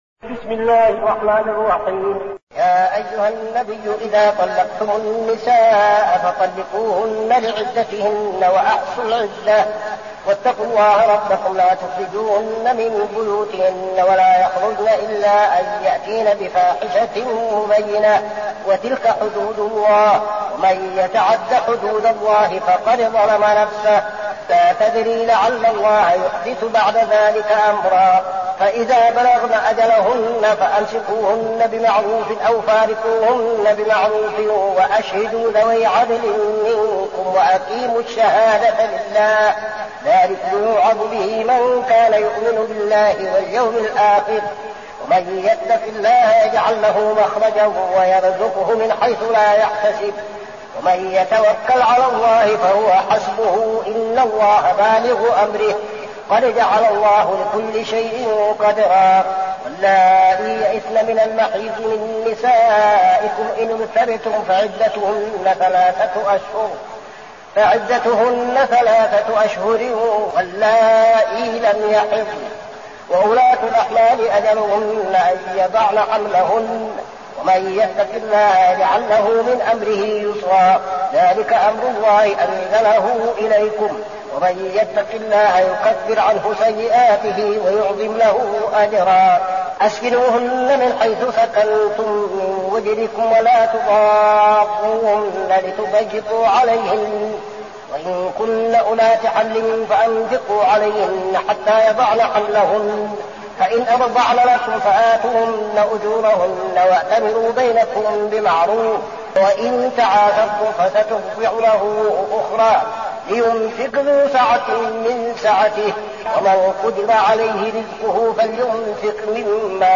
المكان: المسجد النبوي الشيخ: فضيلة الشيخ عبدالعزيز بن صالح فضيلة الشيخ عبدالعزيز بن صالح الطلاق